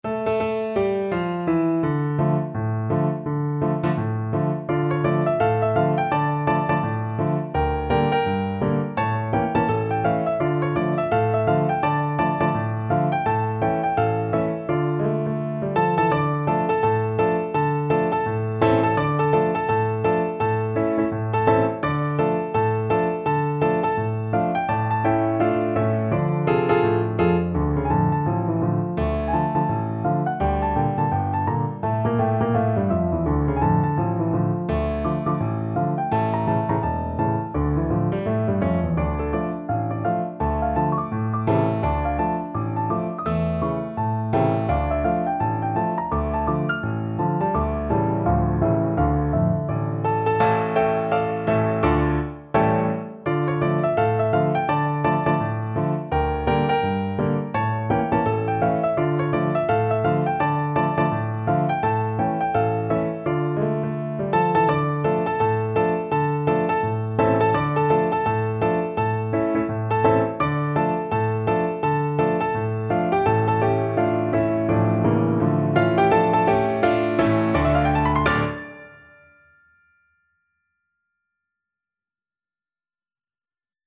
Quick Swing = 84
D minor (Sounding Pitch) (View more D minor Music for Piano Duet )
Piano Duet  (View more Intermediate Piano Duet Music)
Traditional (View more Traditional Piano Duet Music)